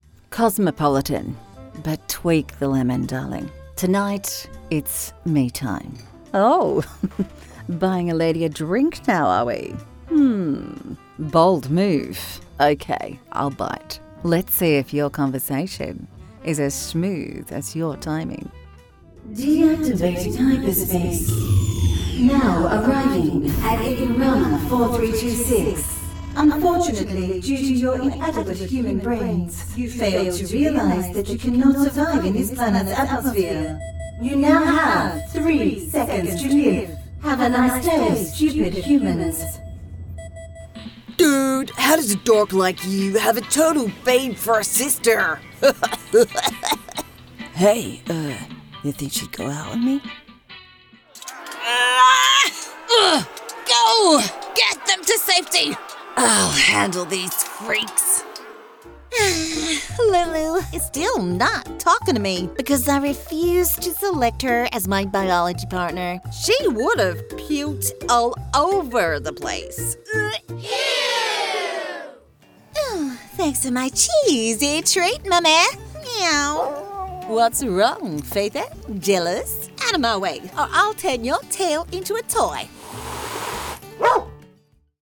Character, Cartoon and Animation Voice Overs
English (Neutral - Mid Trans Atlantic)
Adult (30-50) | Yng Adult (18-29)